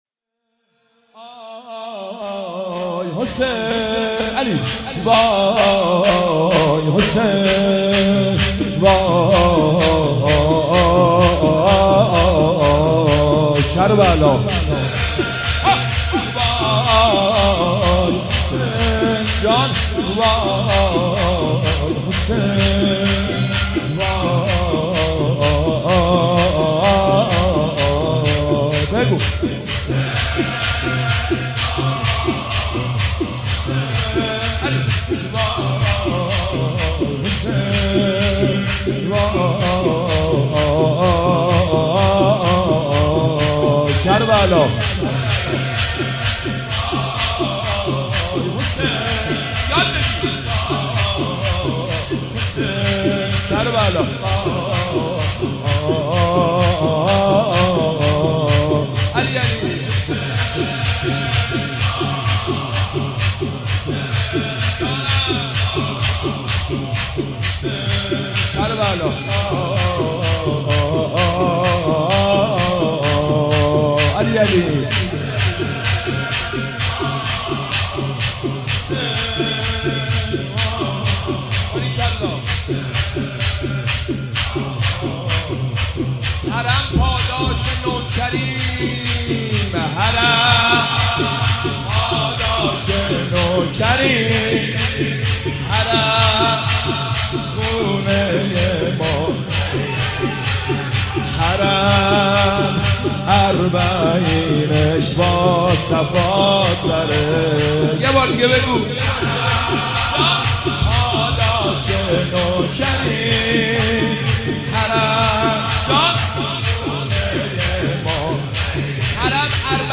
سیب سرخی - شور - حرم پاداش نوکری.mp3